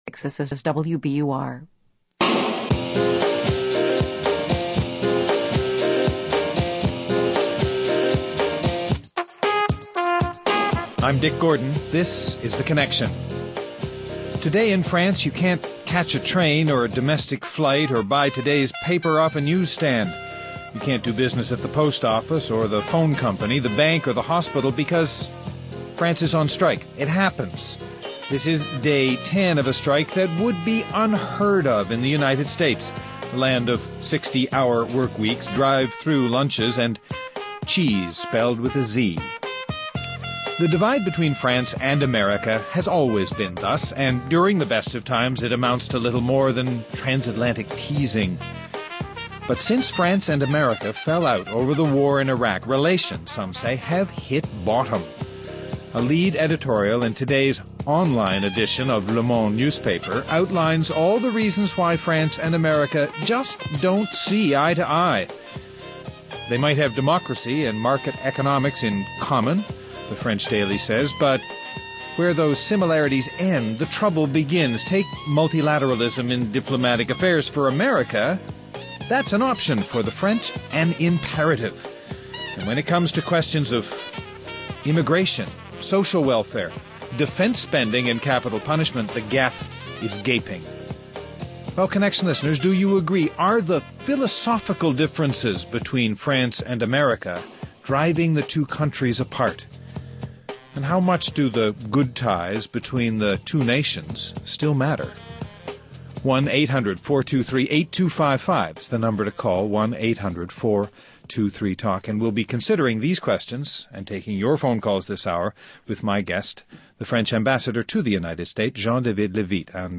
Guests: Jean-David Levitte, French Ambassador to the United States